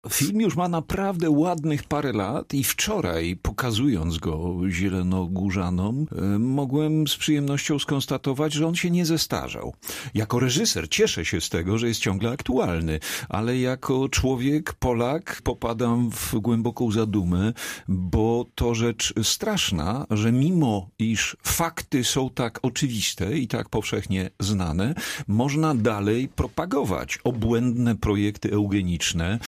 Cała rozmowa z Grzegorzem Braunem dostępna jest tutaj.